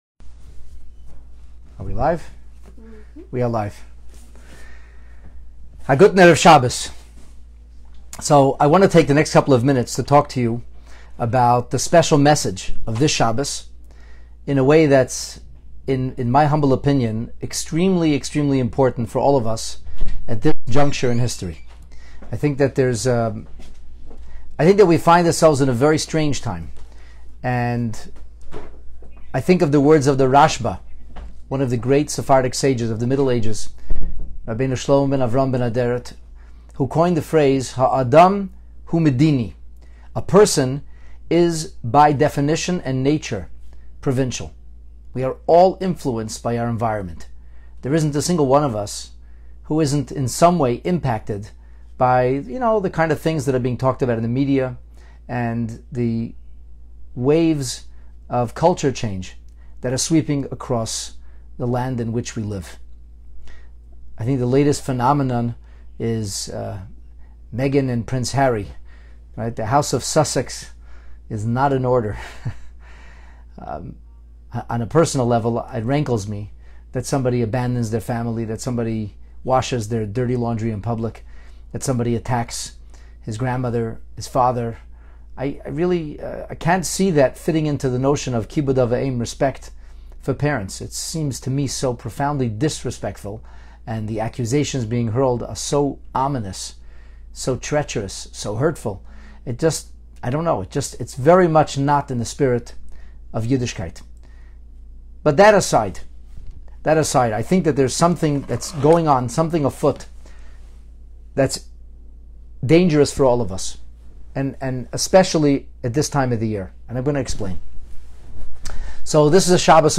Shabbat Vayakhel Pekudei Early Sermon